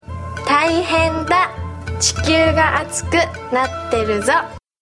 Senryu Shimizu Saki
shimizu_saki_senryu.mp3